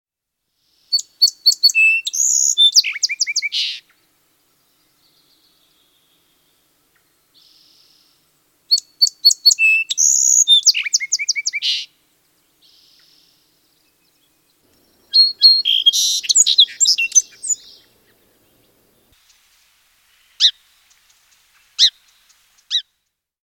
Song Sparrow
sparrow.mp3